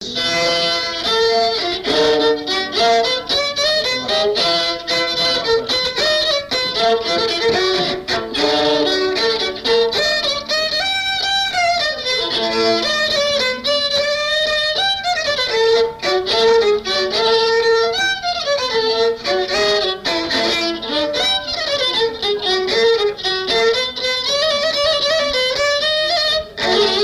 Valse
danse : valse
Pièce musicale inédite